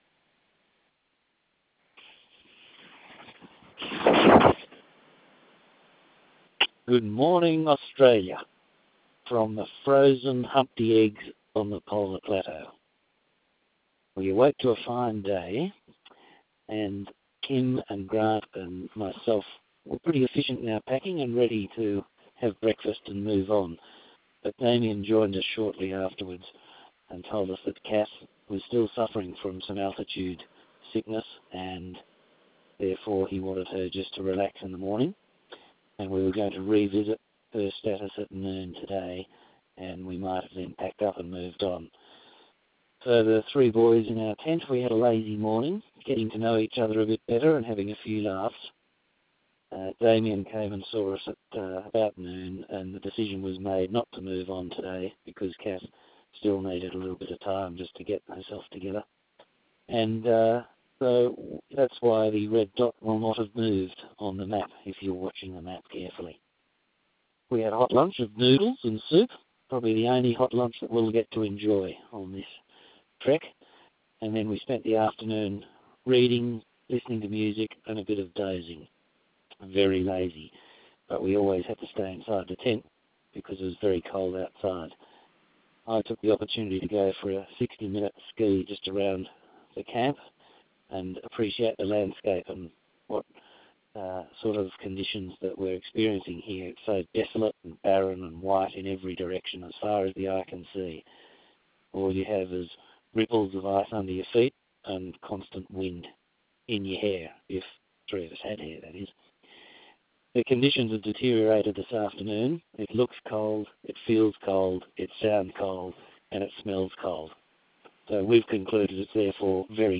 you sound so calm and measured, despite the obvious challenges.